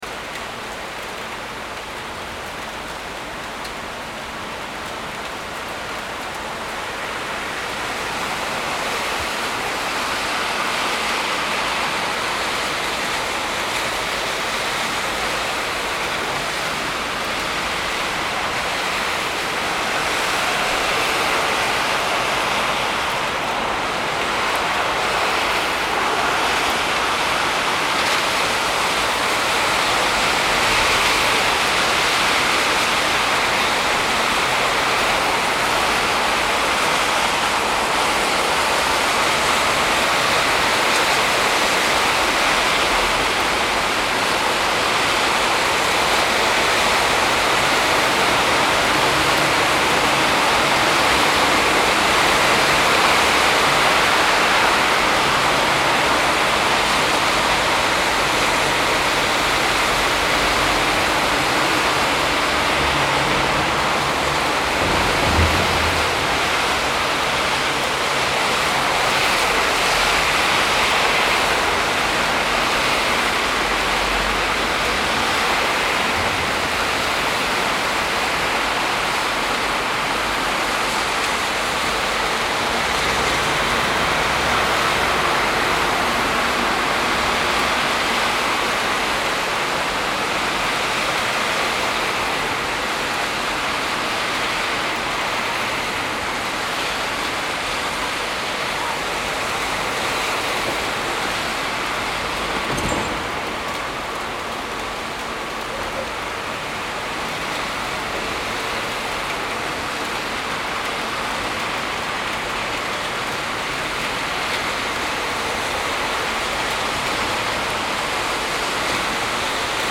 Noisy-urban-street-during-rainfall-sound-effect.mp3